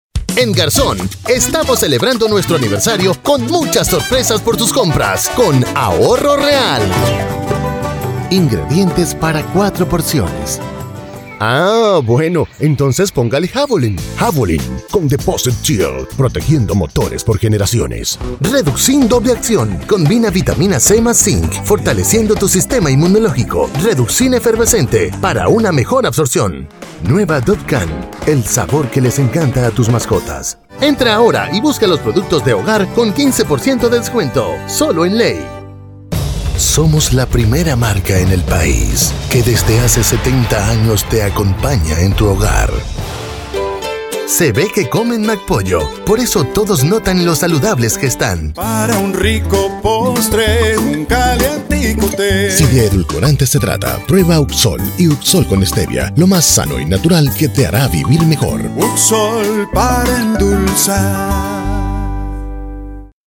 acento neutro
voz en off